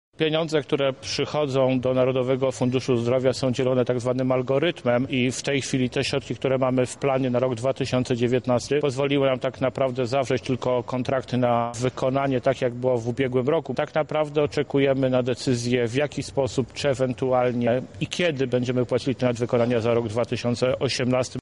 O tym, jak dysponowane są pieniądze w systemie ochrony zdrowia mówi Karol Tarkowski, dyrektor lubelskiego oddziału Narodowego Funduszu Zdrowia.